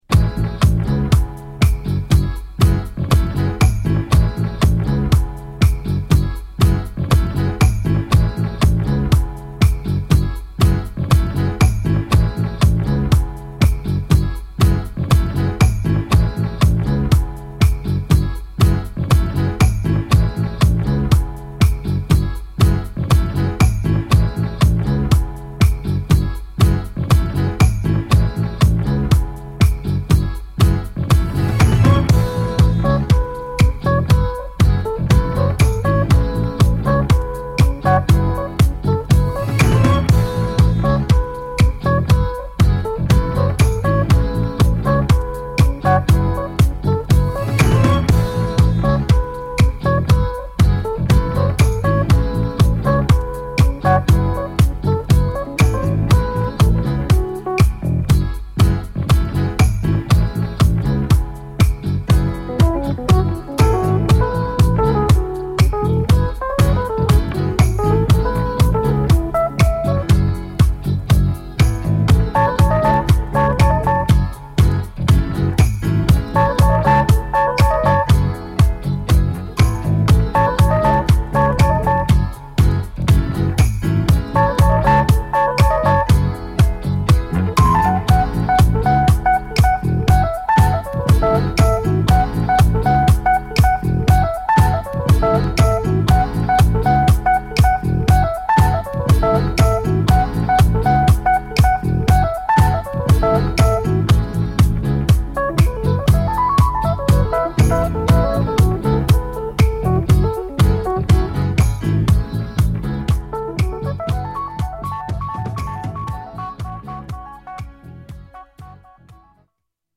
エレピ・ソロ・パートをはじめとするインストパートを主軸にした